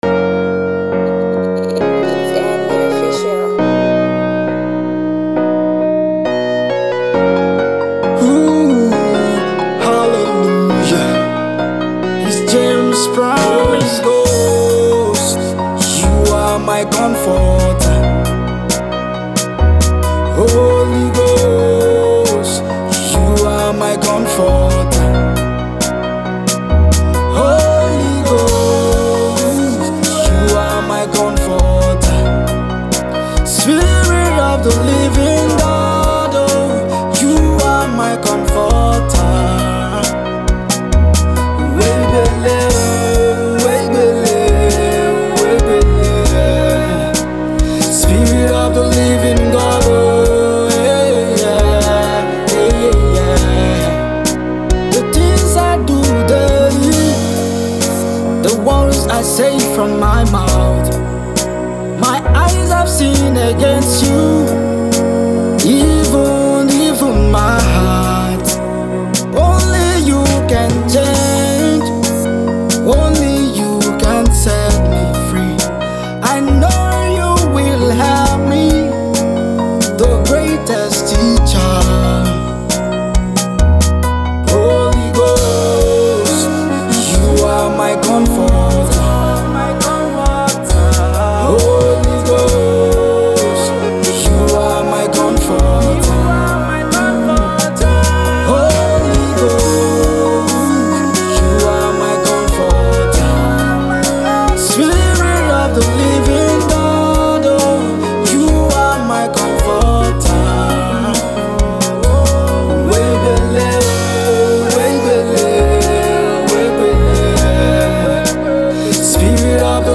Worship Single